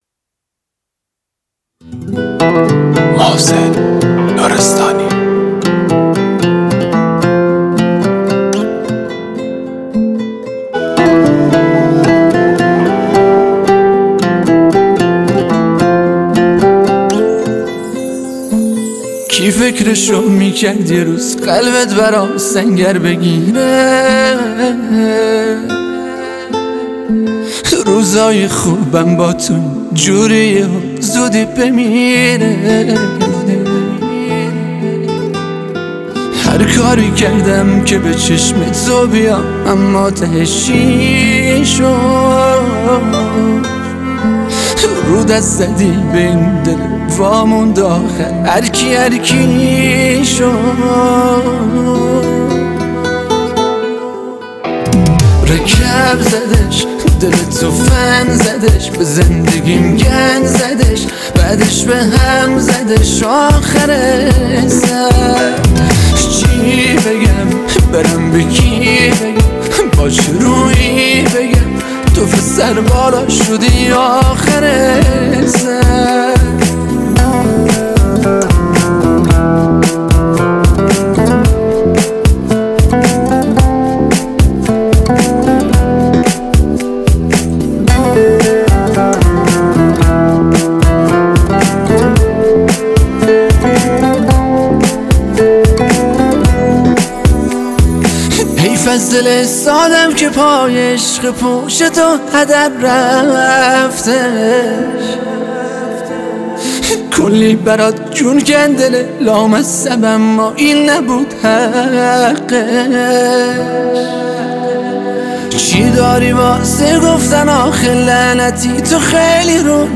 ترانه کردی